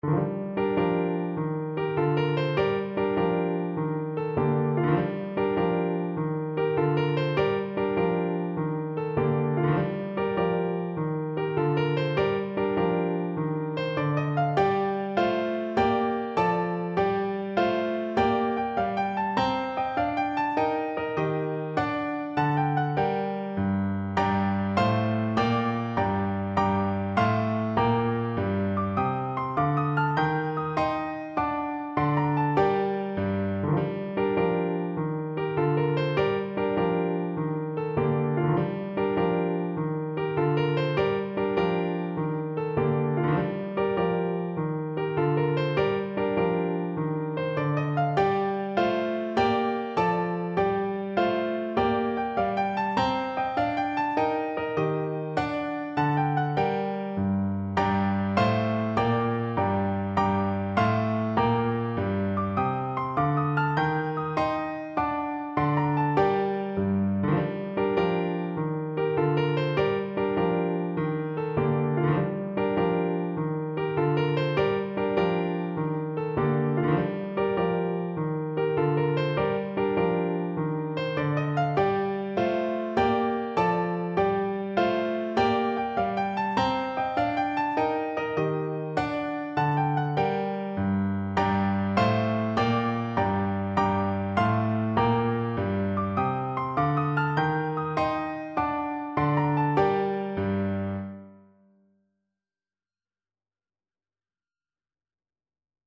Steady March . = c.100
6/8 (View more 6/8 Music)
Clarinet  (View more Easy Clarinet Music)